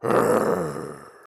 zombieattack.mp3